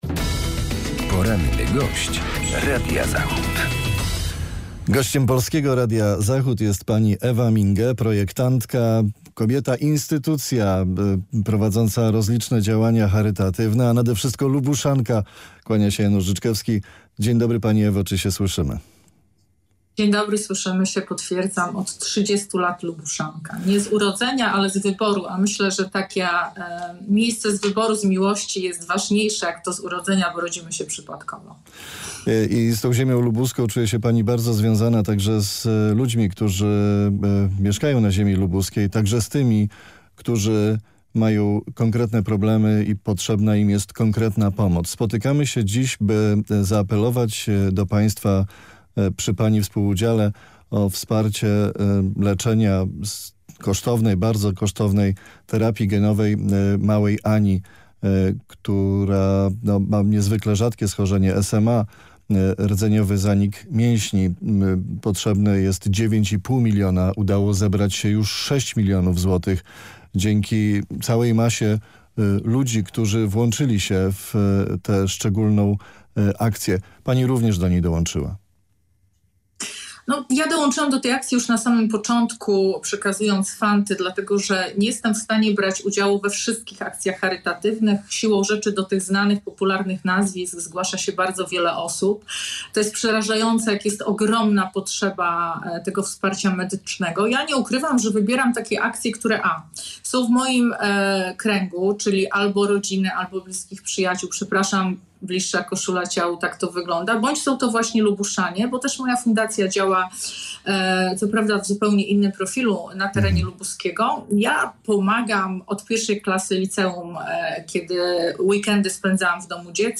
Z projektantką mody i społeczniczką rozmawia